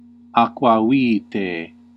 Ääntäminen
US : IPA : [ˈspɪɹ.ɪts]